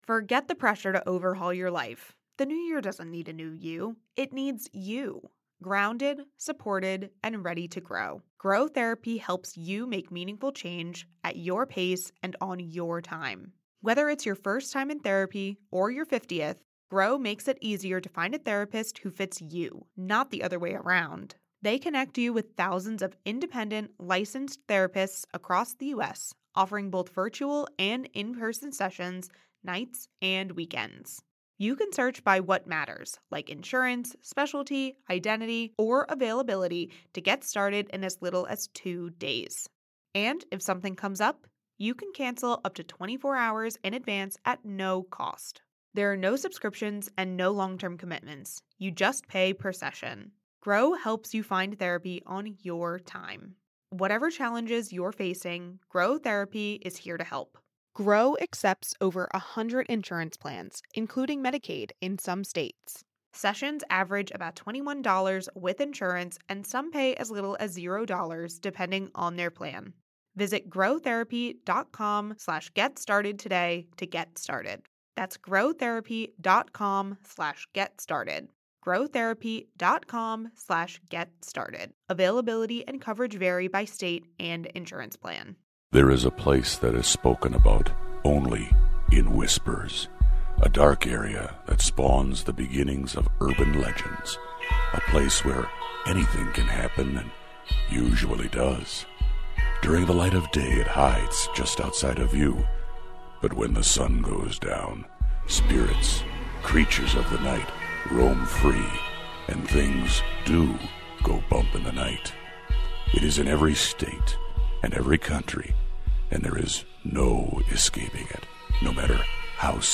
This is the best in paranormal talk radio.